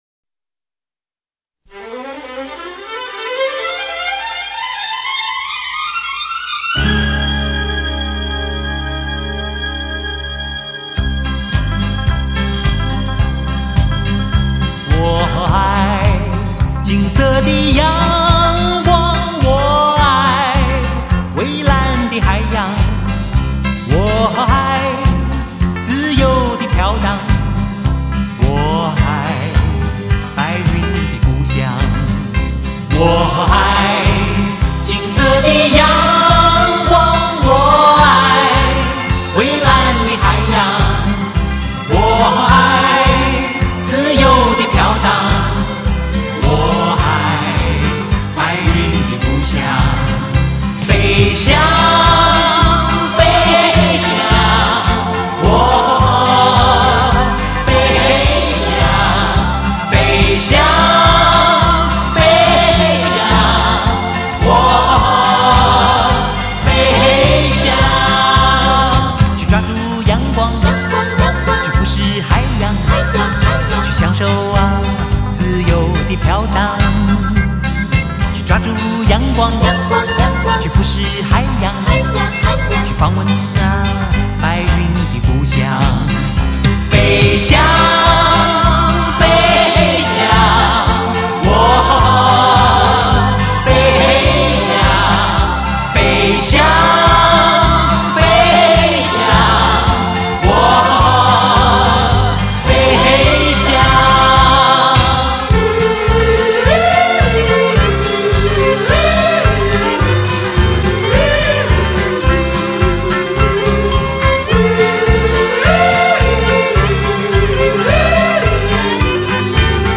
男声极品 气势澎湃